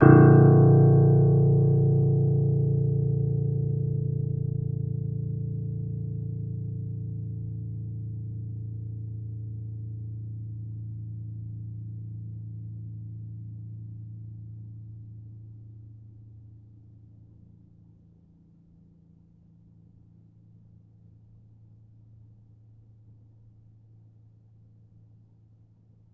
healing-soundscapes/Sound Banks/HSS_OP_Pack/Upright Piano/Player_dyn3_rr1_000.wav at main